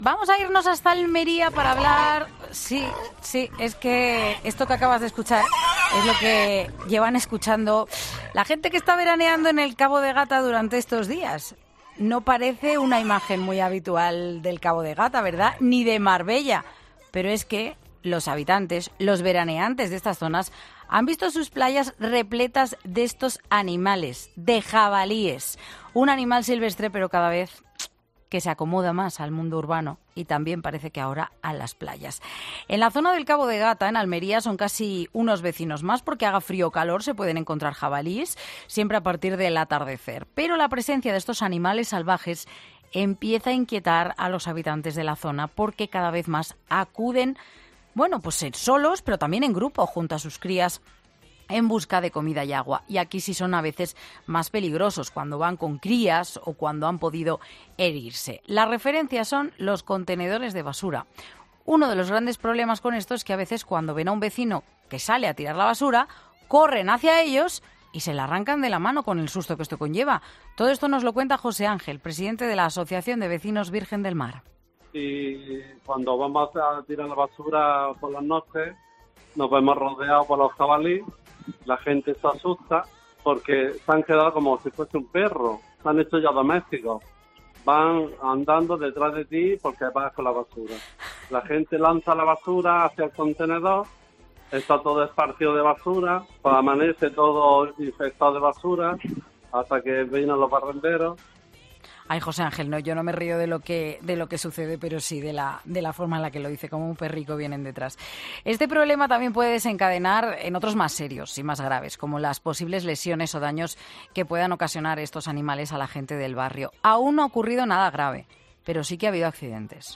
Escucha la entrevista completa al vecino de Almería y a un experto, sobre los jabalíes en zonas rurales